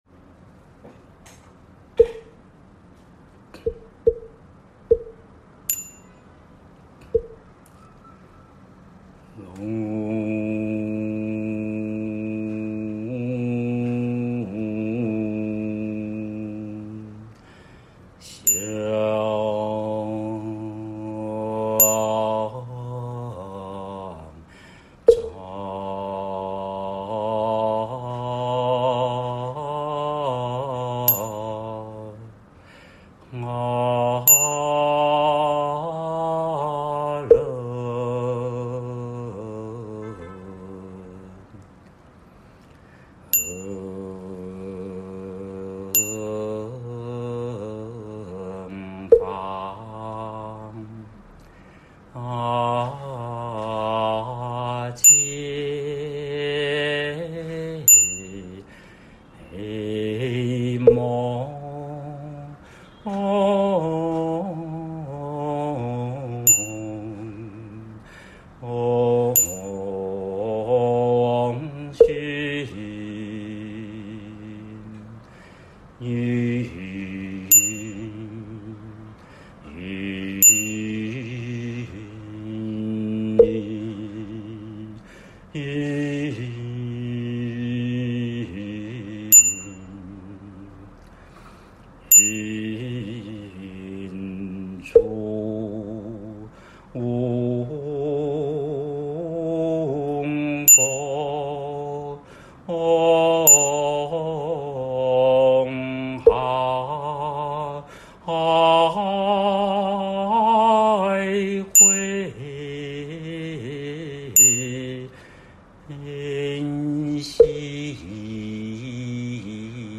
7、《自宅安座法会》梵呗仪轨及教学音档 佛教正觉同修会_如来藏网